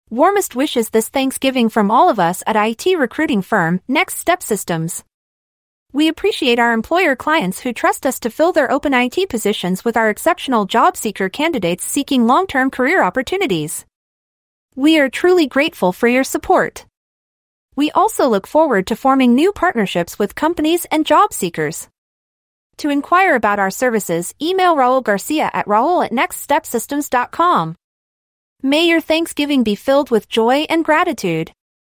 A Thanksgiving Message from IT Recruiting Firm, Next Step Systems Using Artificial Intelligence (AI)
Please take a moment to listen to a Happy Thanksgiving holiday audio message from our IT recruiting firm generated by Artificial Intelligence (AI).